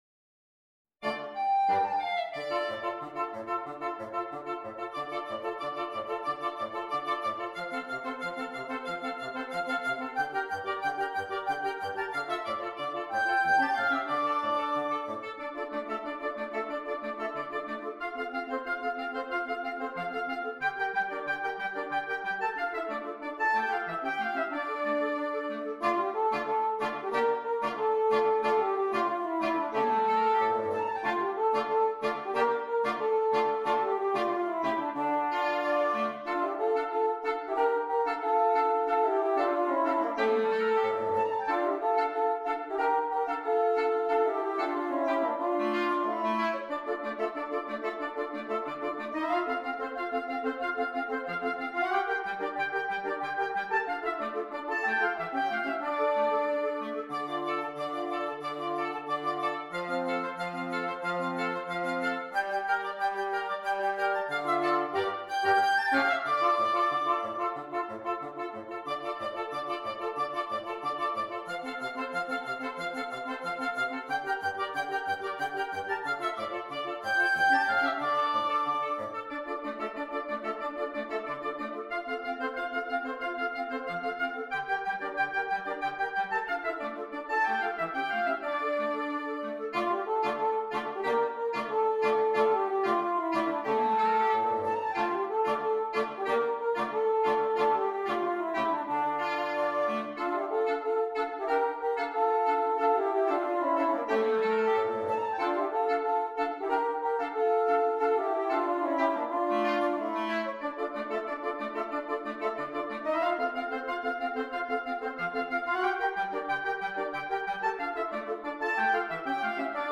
Woodwind Quintet
Traditional